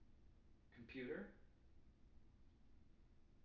wake-word
tng-computer-387.wav